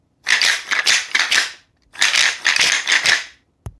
ワサンバ ひょうたんマラカス アフリカン民族楽器 （p575-11）
ブルキナファソで作られた「ワサンバ」と呼ばれる民族楽器でひょうたんと木を使ったアフリカンマラカスです。
大小8枚の丸いひょうたん片がありすべて共鳴するとかなり大きな音を出します。
この楽器のサンプル音
ひょうたん、木